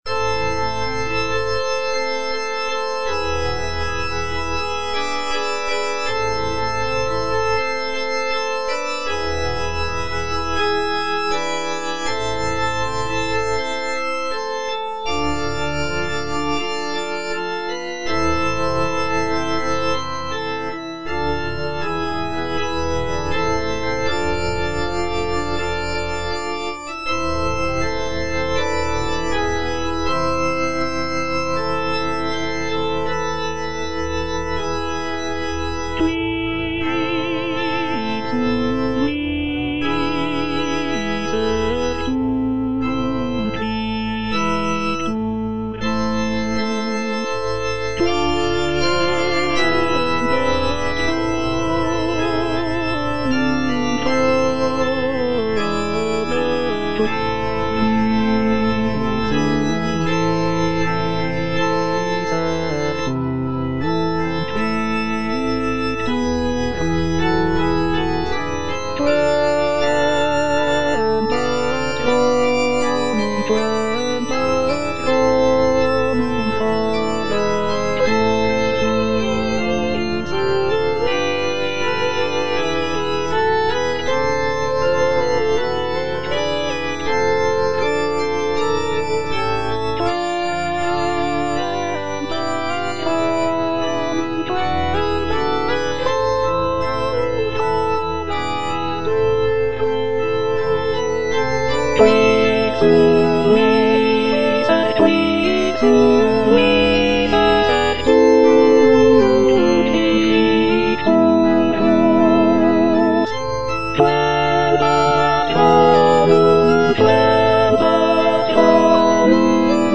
Alto (Emphasised voice and other voices) Ads stop